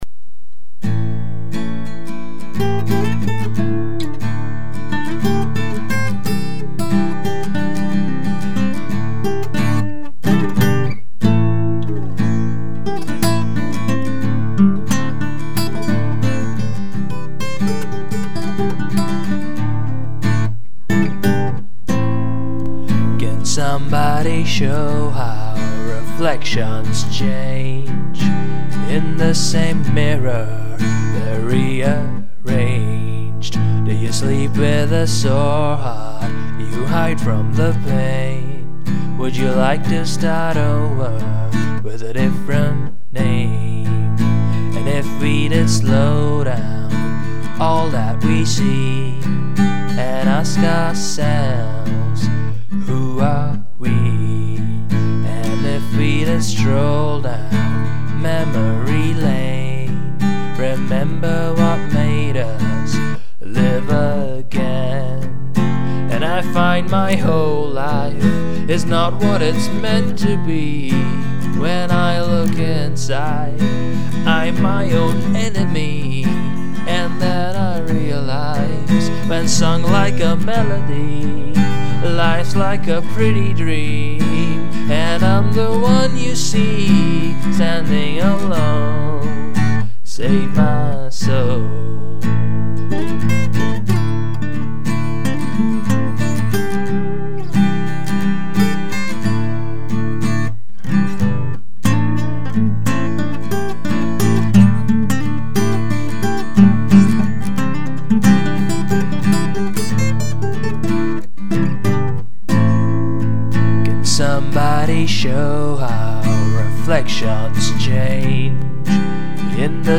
mp3 lyrics (Mar 2006)   Hippie music all the way. Not bad for a night's recording.